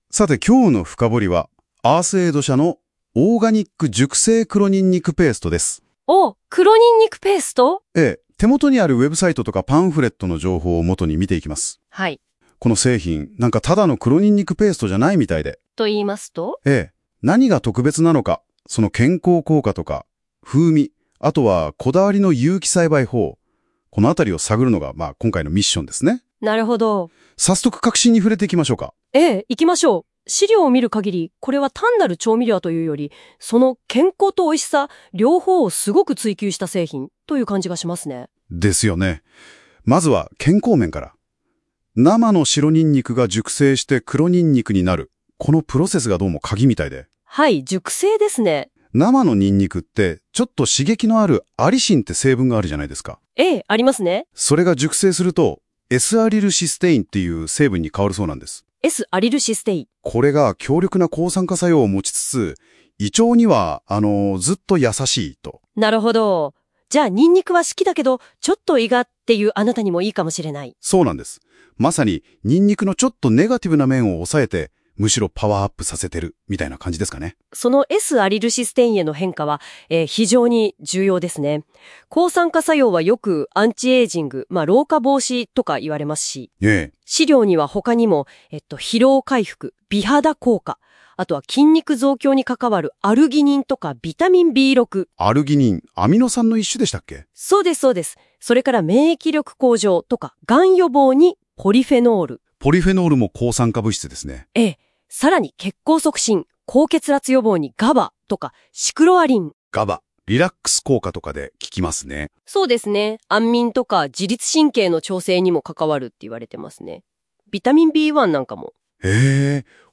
AI音声解説《黒にんにくペースト深掘り：健康・旨味・こだわり製法を紐解く》
Leaflet-Audio-Commentary.mp3